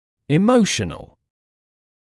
[ɪ’məuʃənl][и’моушэнл]эмоциональный